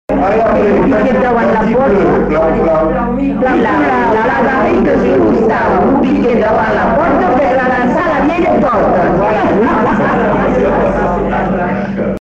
Lieu : Bazas
Genre : forme brève
Type de voix : voix de femme
Production du son : récité